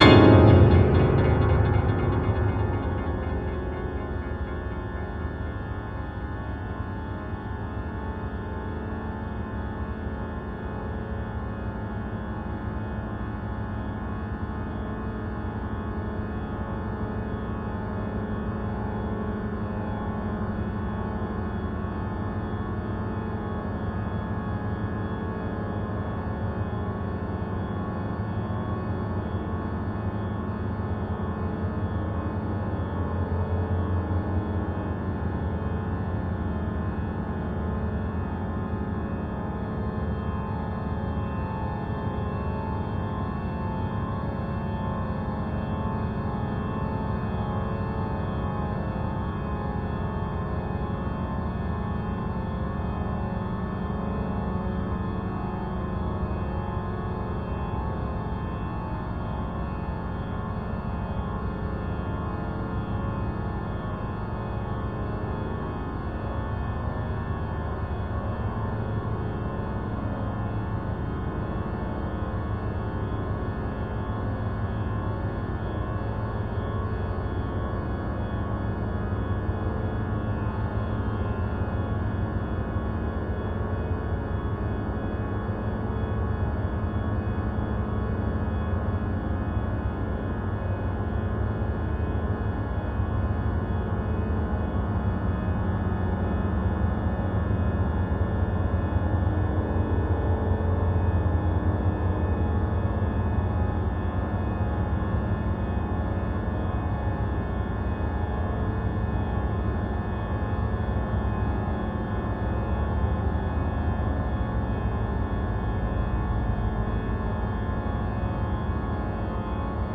非実存ピアノによるオーケストレーション作品。
サスティーン音と反響における倍音構造は、綴れ織り、また打ち返す波のように、
有機的に絡み合いながら鳴り響く。
C# Minor Arabic
先端的テクノ・マニアにもお薦めのアルバム。